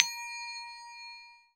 TMPL BELL  S.WAV